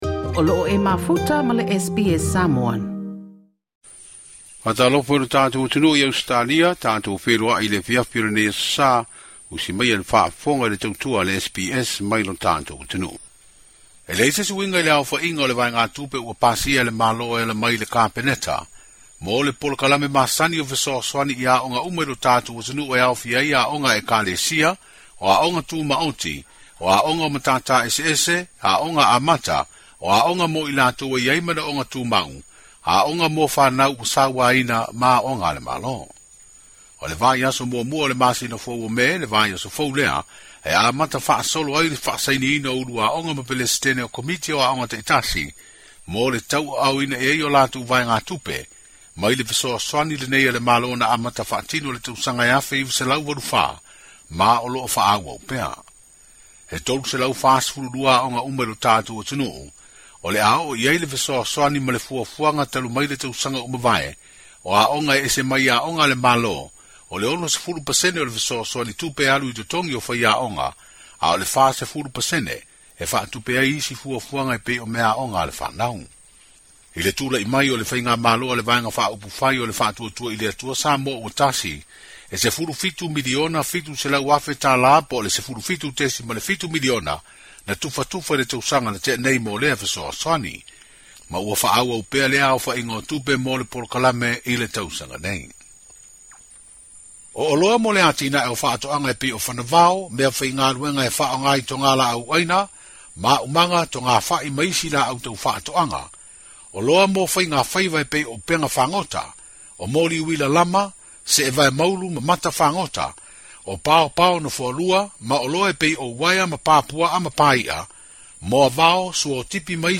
O loo pepesi le flu i Samoa ma le misela i Amerika Samoa. Se talanoaga ma le faatonu o le matagaluega o le soifua maloloina i Samoa.